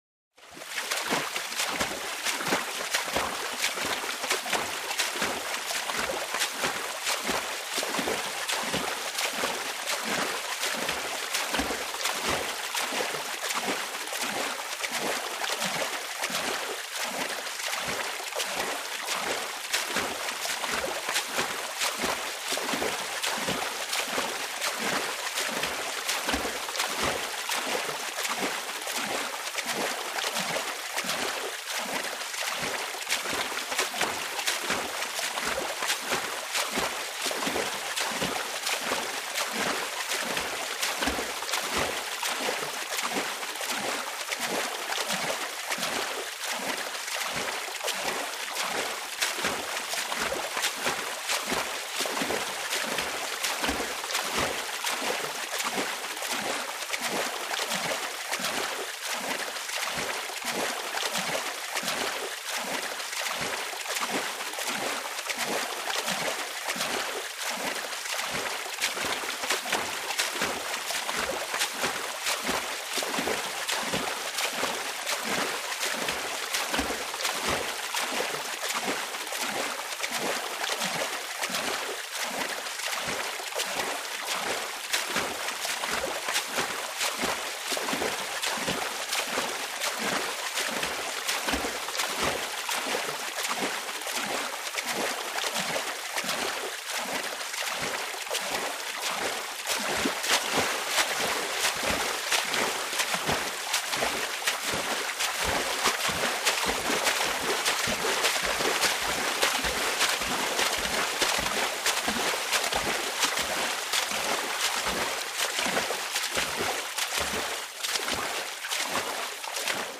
Paddle Boat - Fast, Oars Or Paddles